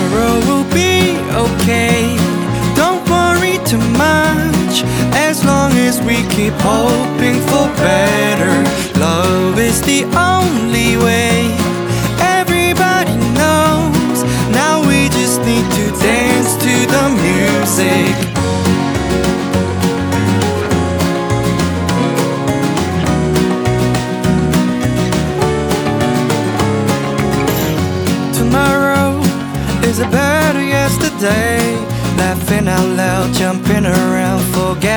2025-05-30 Жанр: Альтернатива Длительность